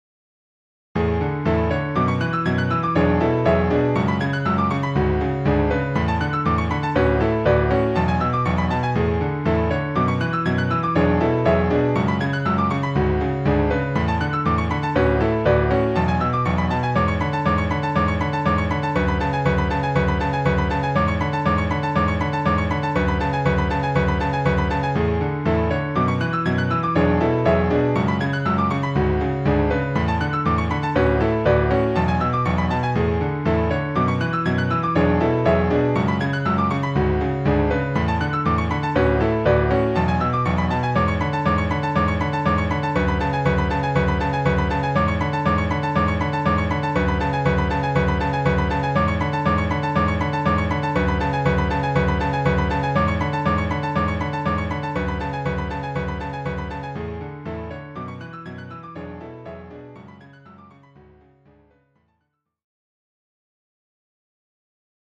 ジャンル：インストゥルメンタル
何だか、料理番組とかで流れていそうな感じですね(^ω^)